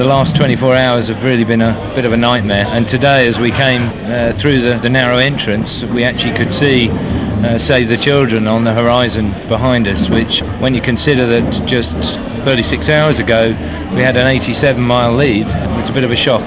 The following clips were recorded during the race.